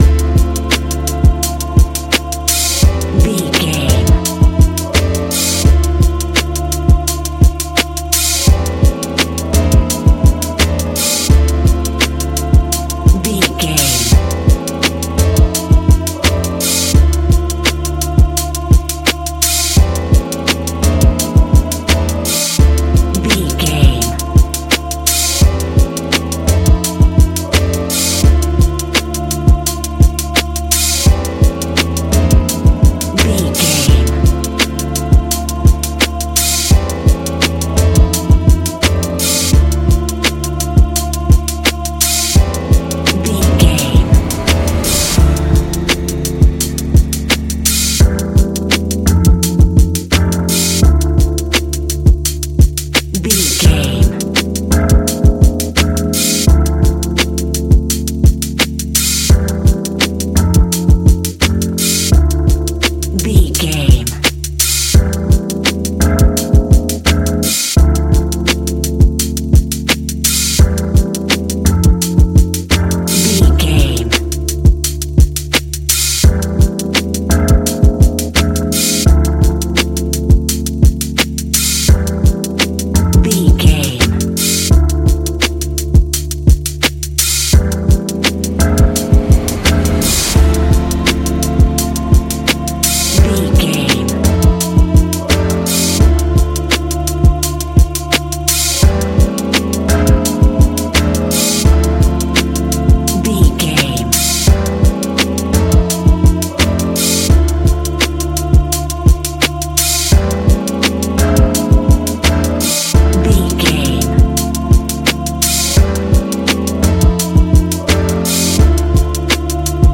Ionian/Major
chilled
laid back
sparse
new age
chilled electronica
ambient
atmospheric
morphing